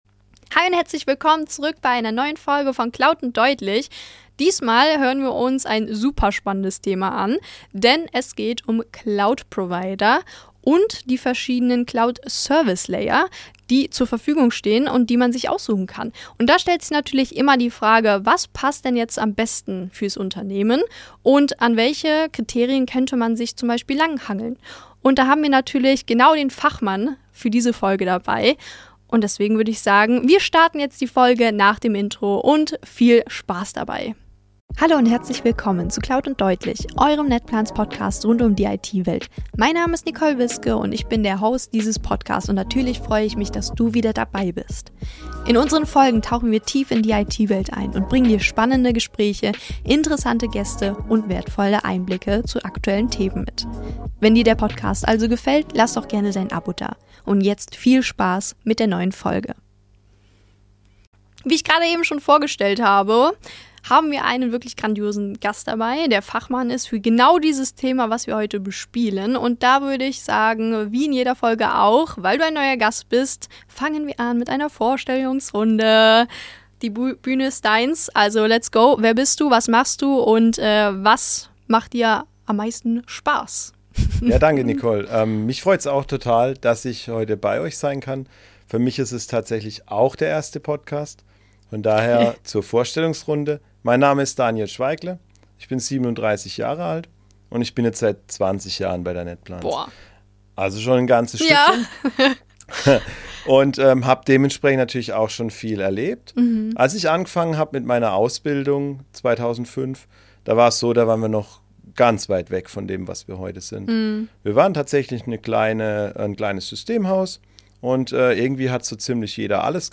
Genau deshalb ist er in dieser Folge zu Gast.